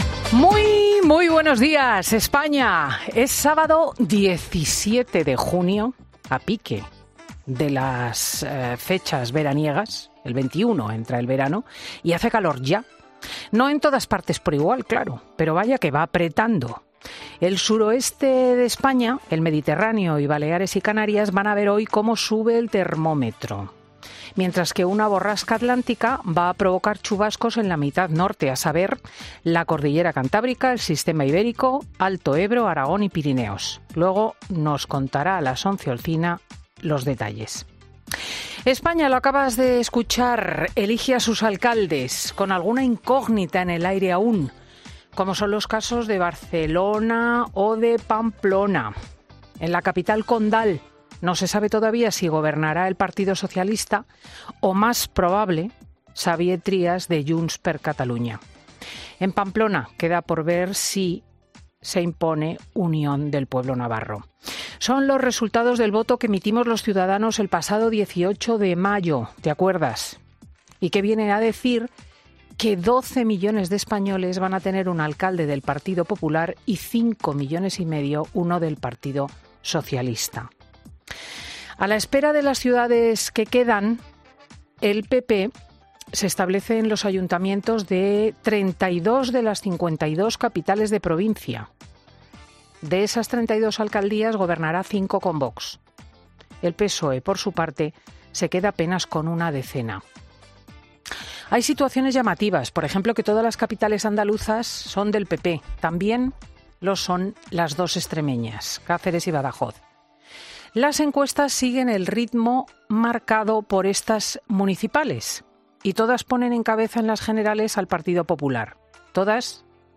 Escucha el monólogo de Cristina López Schlichting de este sábado 17 de junio de 2023